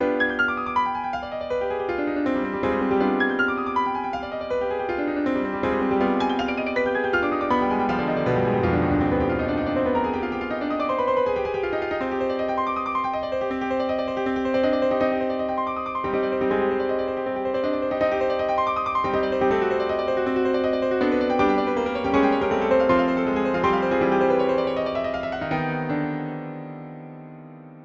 Neue Musik
Sololiteratur
Klavier (1)